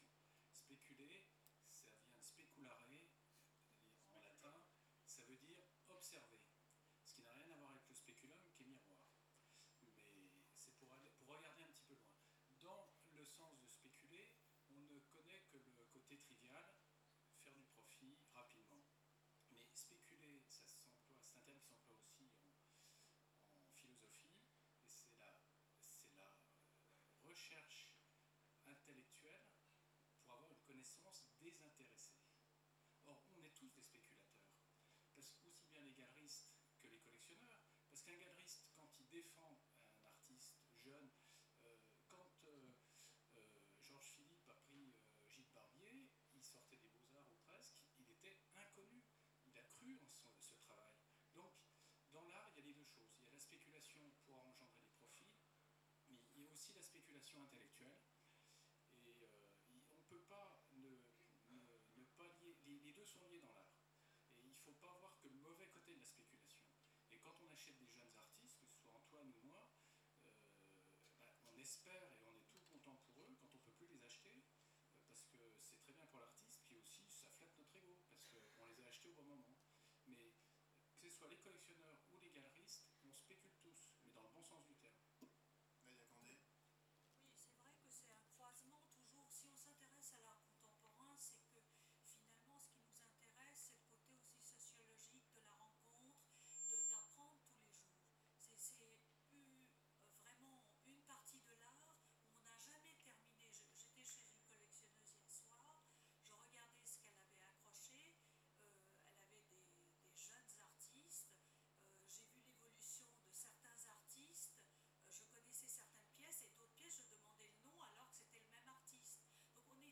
Free admission on presentation of Docks Art Fair ticket Documents audio télécharger le mp3 Table ronde - Partie 1/2 télécharger le mp3 Table ronde - Partie 2/2